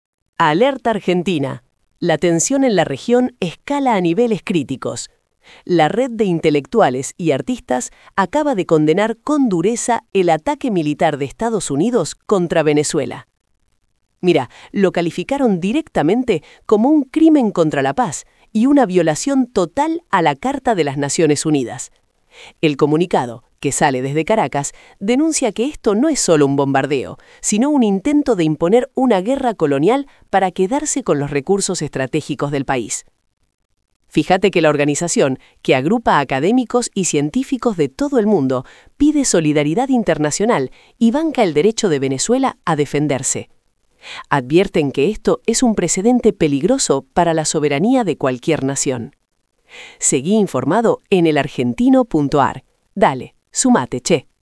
— 🎙 Resumen de audio generado por IA.